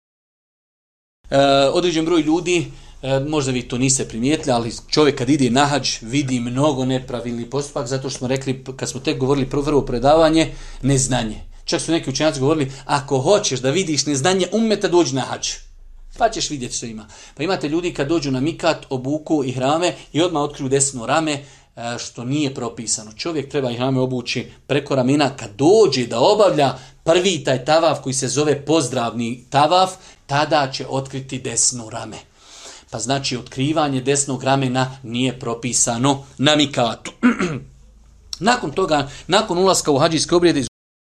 Odgovor prenosimo iz serijala predavanja o pravnim propisima hadža